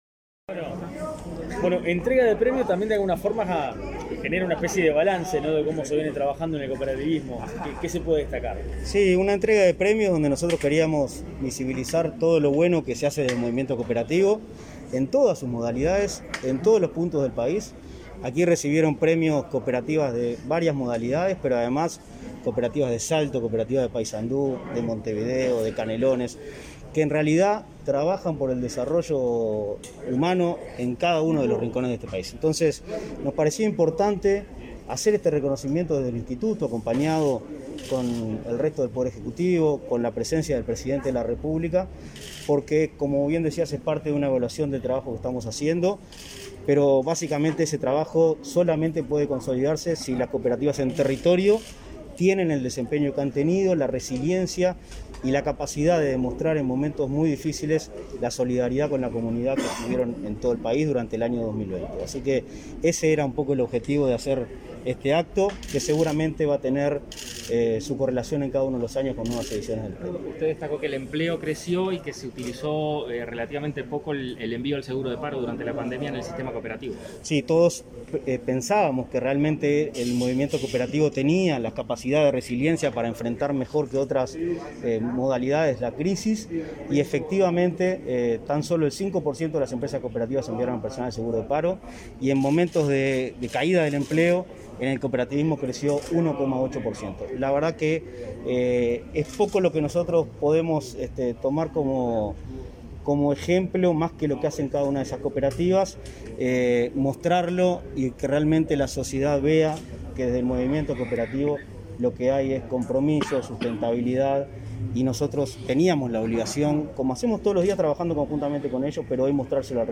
Declaraciones a la prensa del presidente del Instituto Nacional del Cooperativismo, Martín Fernández
Declaraciones a la prensa del presidente del Instituto Nacional del Cooperativismo, Martín Fernández 28/10/2021 Compartir Facebook X Copiar enlace WhatsApp LinkedIn Tras participar en el acto de entrega del premio Miguel Cardozo, destinado a cooperativas y organizaciones de la economía social y solidaria, este 28 de octubre, Fernández efectuó declaraciones a la prensa.